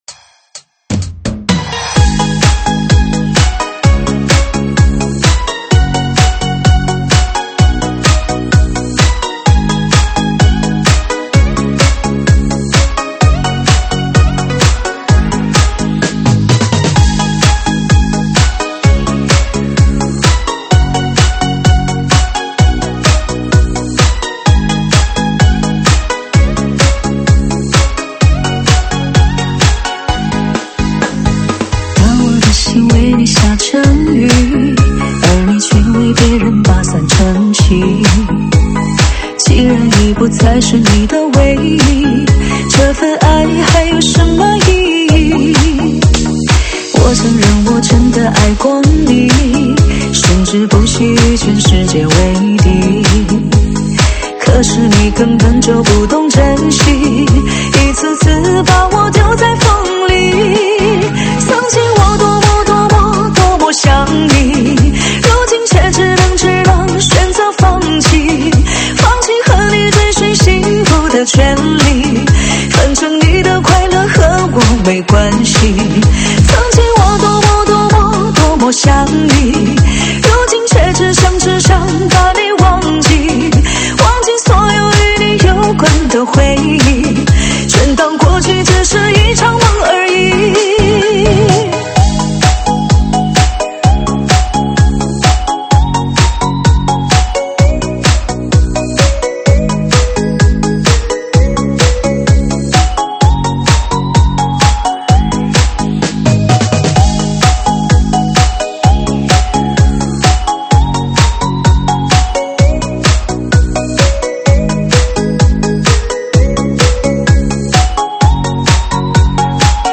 舞曲类别：周榜单